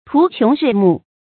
途窮日暮 注音： ㄊㄨˊ ㄑㄩㄥˊ ㄖㄧˋ ㄇㄨˋ 讀音讀法： 意思解釋： 猶日暮途窮。